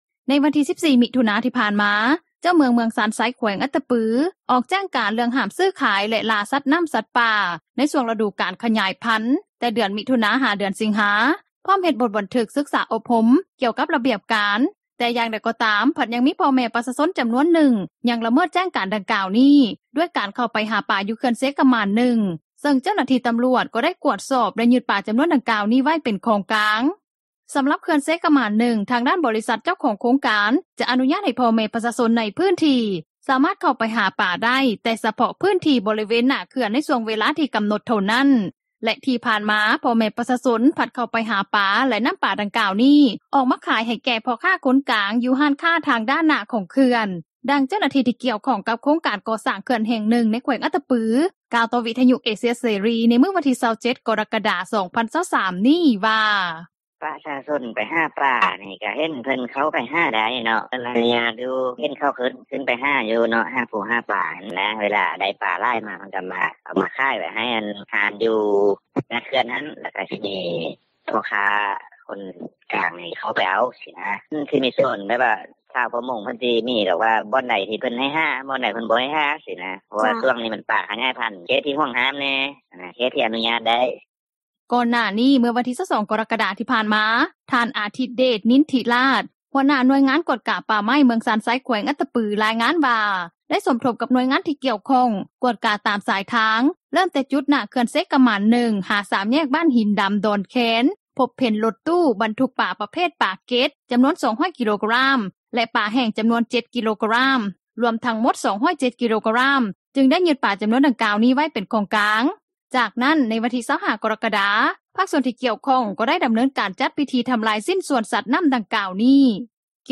ດັ່ງຊາວບ້ານ ຢູ່ເມືອງຊານໄຊ ແຂວງອັດຕະປື ກ່າວຕໍ່ວິທຍຸເອເຊັຽຣີ ໃນມື້ດຽວກັນນີ້ວ່າ:
ດັ່ງຊາວບ້ານ ຢູ່ບໍຣິເວນເຂື່ອນໄຟຟ້ານໍ້າກົງ ນາງນຶ່ງ ກ່າວວ່າ: